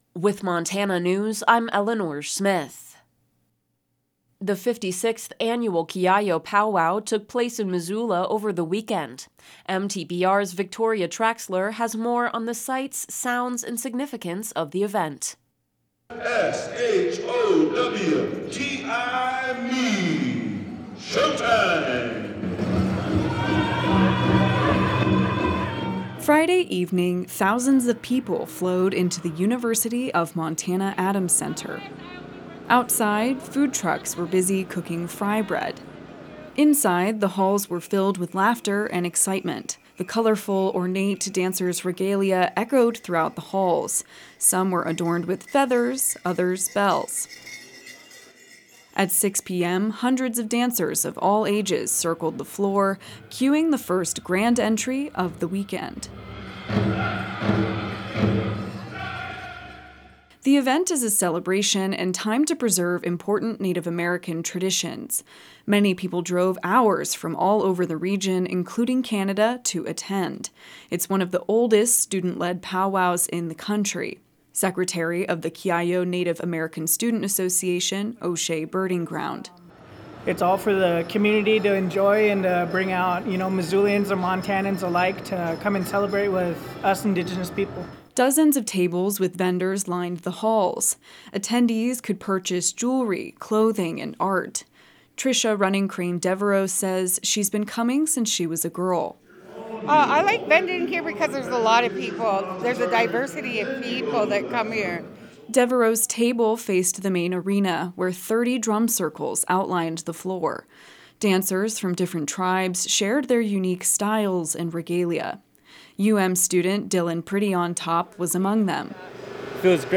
Statewide news from Montana Public Radio
mn-kiyiyo-pow-wow-04-22-2.mp3